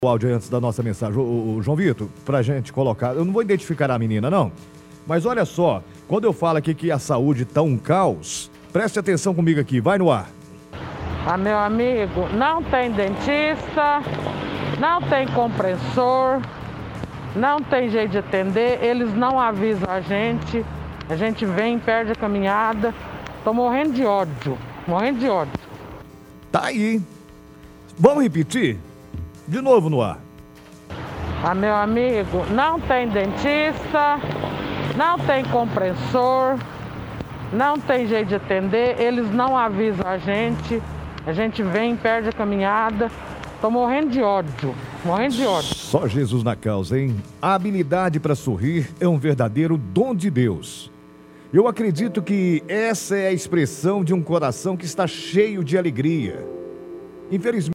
Ligação Ouvintes